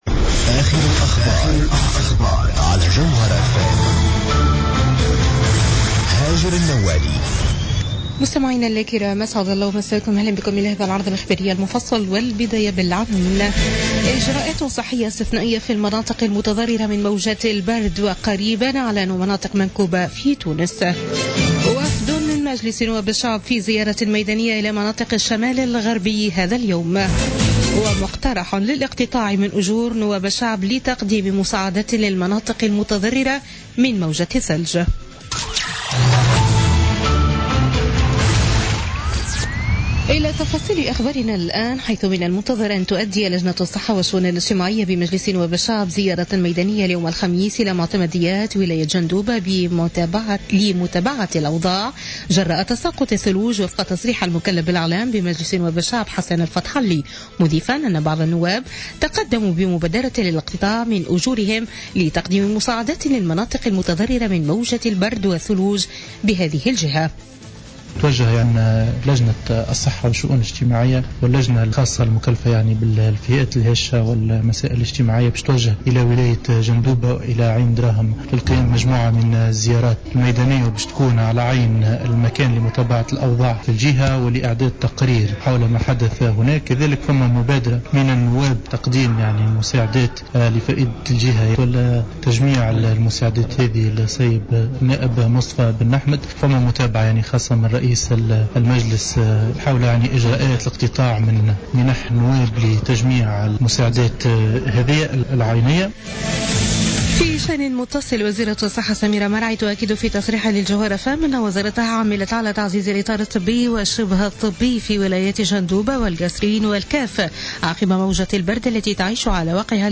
Journal Info 00h00 du jeudi 19 janvier 2017